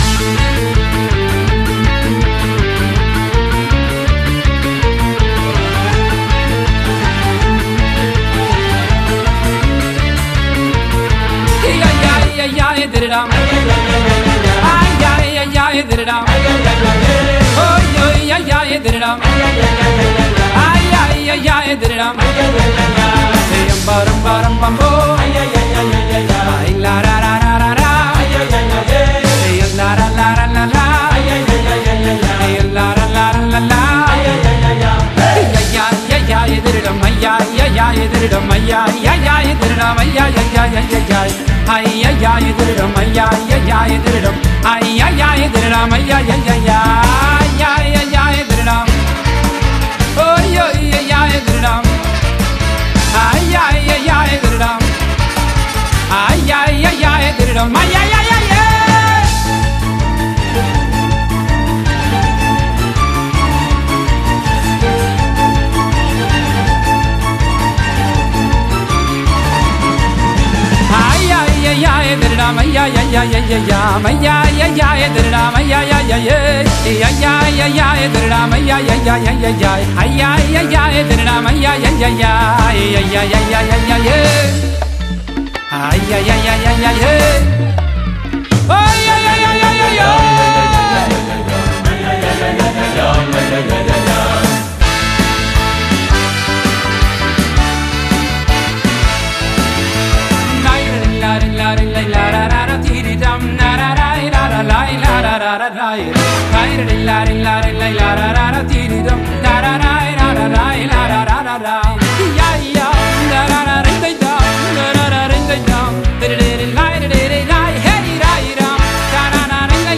תזמורת החתונות
לזמר החסידי